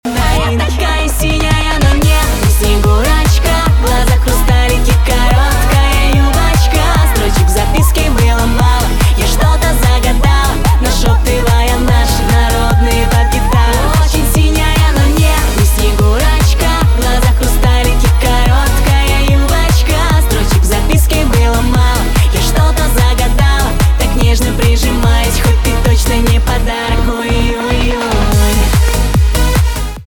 поп
новогодние , праздничные , битовые
веселые